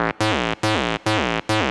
SNTHBASS032_DANCE_140_A_SC3.wav